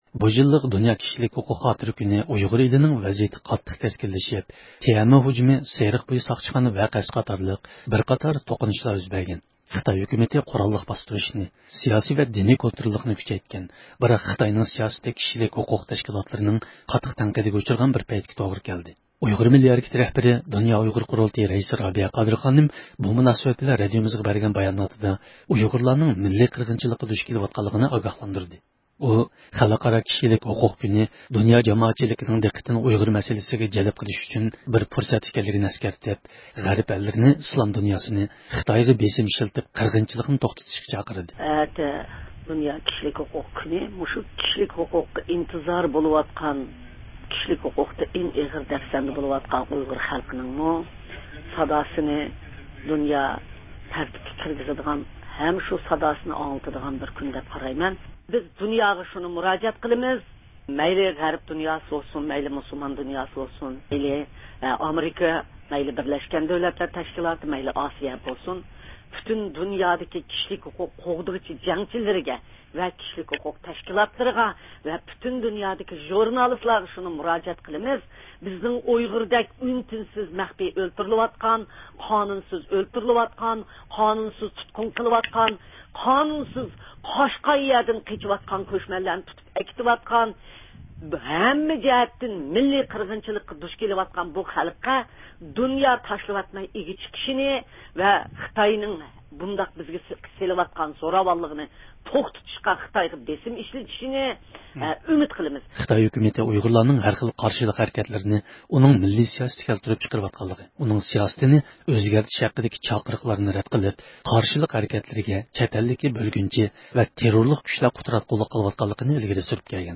ئۇيغۇر مىللىي ھەرىكىتى رەھبىرى، د ئۇ ق رەئىسى رابىيە قادىر خانىم، بۇ مۇناسىۋەت بىلەن رادئىومىزغا بەرگەن باياناتىدا، ئۇيغۇرلارنىڭ مىللىي قىرغىنچىلىققا دۇچ كېلىۋاتقانلىقىنى ئاگاھلاندۇردى.